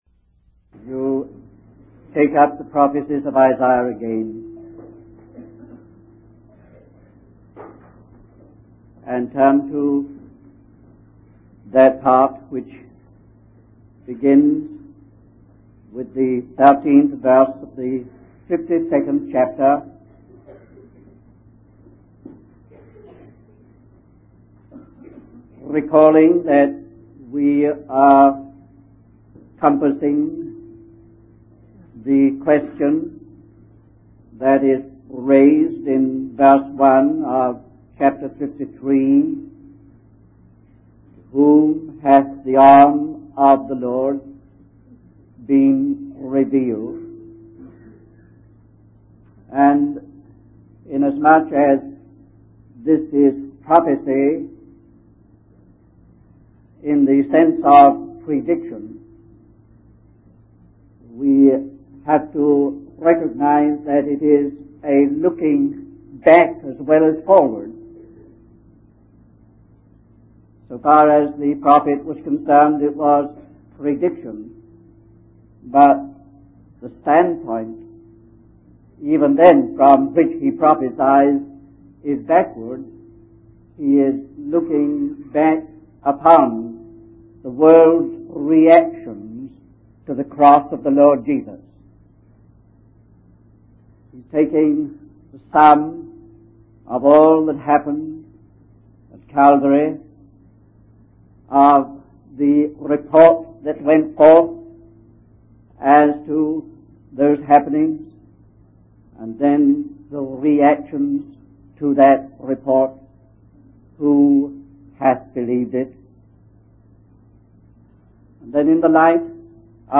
In this sermon, the speaker discusses the new prospect and hope that comes with the foundation laid by the cross. The Lord is focused on recovering, restoring, and rebuilding His people. The speaker highlights the bright and hopeful notes struck in the later chapters of Isaiah, but also acknowledges the presence of dark clouds that bring uncertainty.